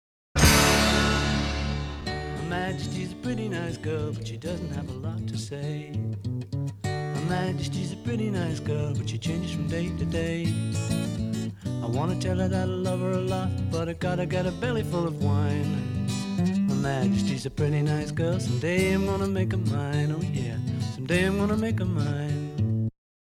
It is a brief tongue-in-cheek music hall song.
English rock band